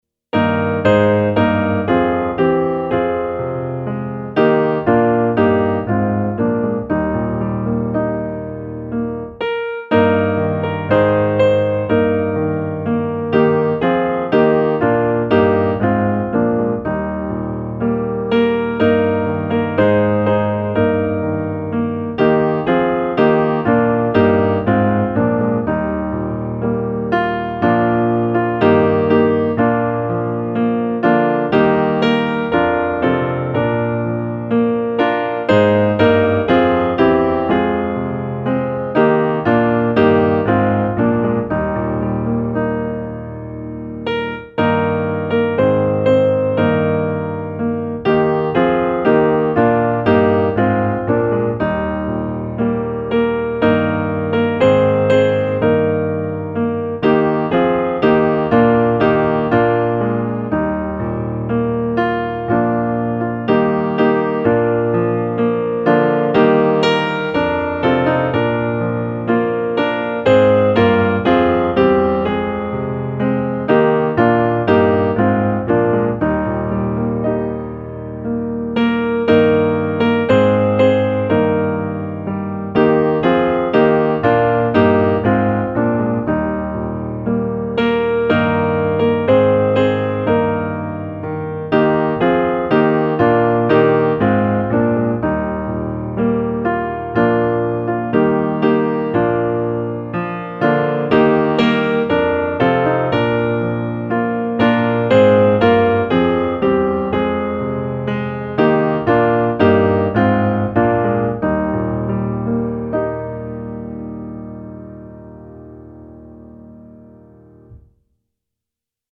Nu tacka Gud, allt folk - musikbakgrund